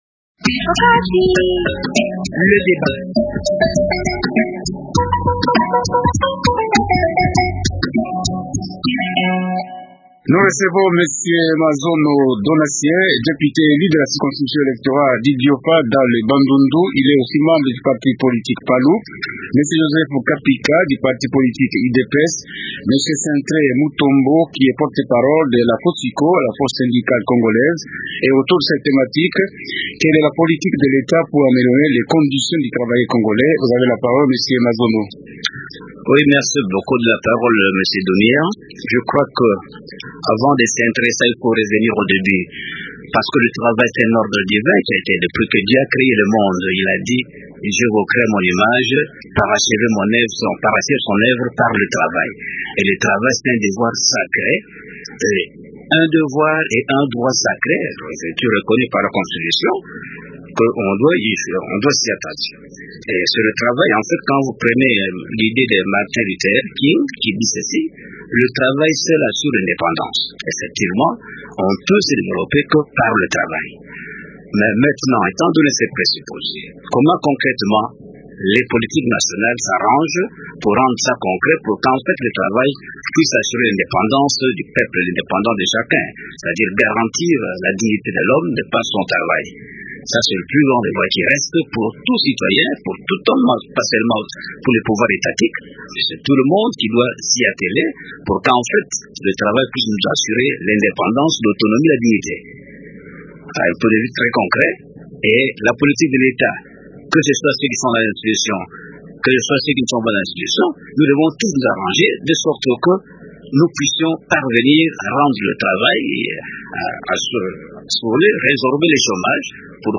Le représentant de l’opposition, celui de la majorité au pouvoir ainsi que celui de la société civile dénoncent les conditions dans lesquelles travaille le Congolais. Ils fustigent l’attitude des employeurs et proposent des pistes de sortie.